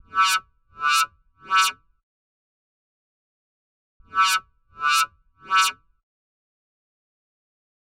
FAIL
cartoon descending fail failure game-over major minor mistake sound effect free sound royalty free Movies & TV